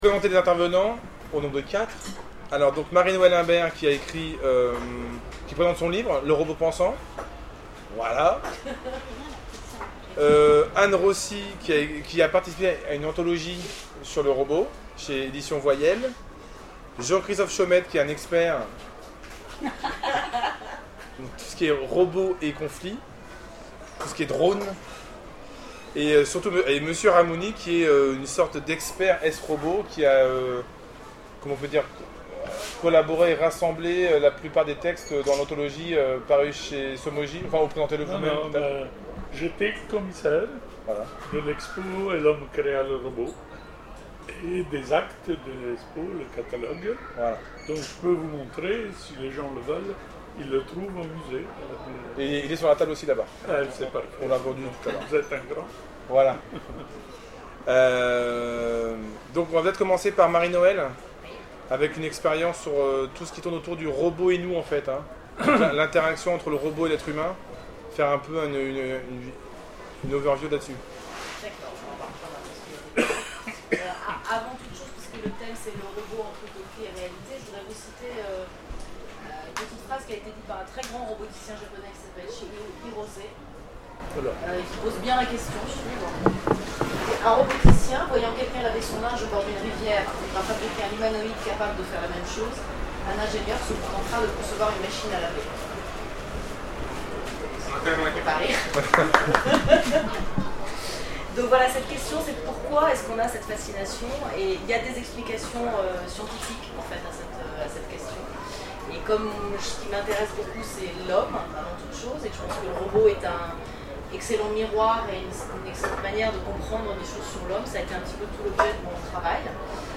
Les Futuriales 2013 : Conférence Le robot : entre utopie et réalité